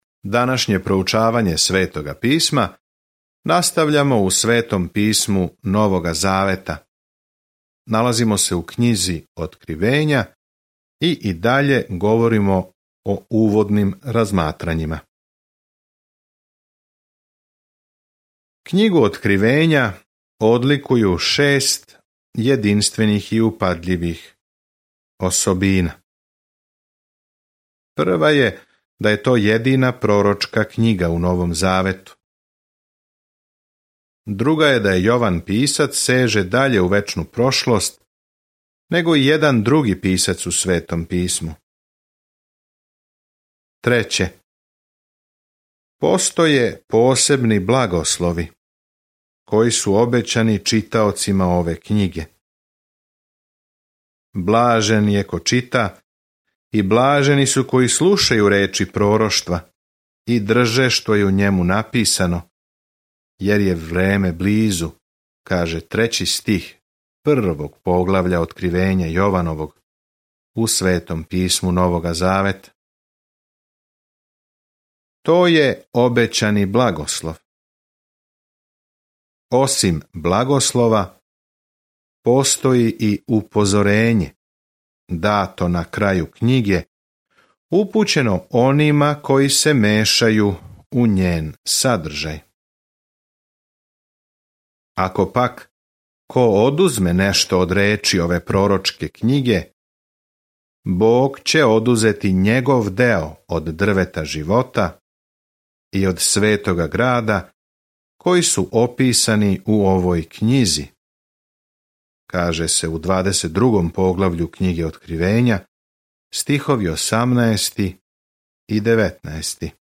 Scripture Revelation 1:1 Day 2 Start this Plan Day 4 About this Plan Откривење бележи крај свеобухватне временске линије историје са сликом о томе како ће се коначно обрачунати са злом и како ће Господ Исус Христ владати у свакој власти, моћи, лепоти и слави. Свакодневно путовање кроз Откривење док слушате аудио студију и читате одабране стихове из Божје речи.